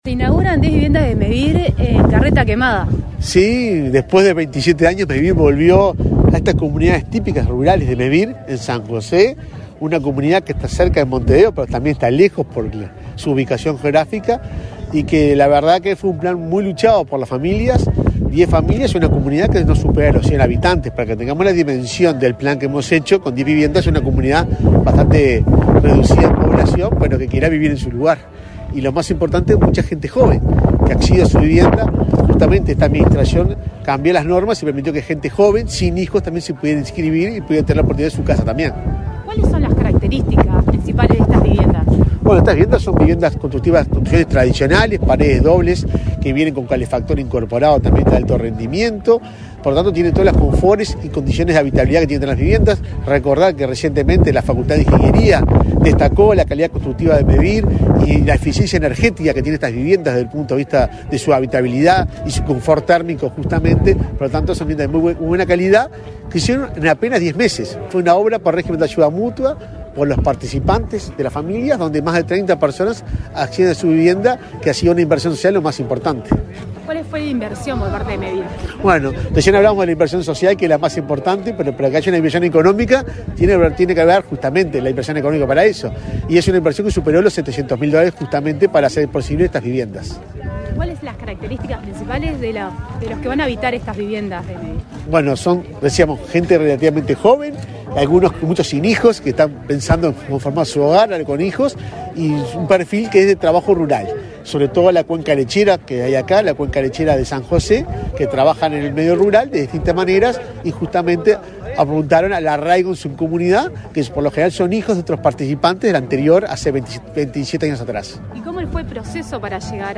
Entrevista al presidente de Mevir, Juan Pablo Delgado
Previo al evento, el presidente de Mevir, Juan Pablo Delgado, realizó declaraciones a Comunicación Presidencial.